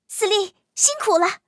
T43战斗返回语音.OGG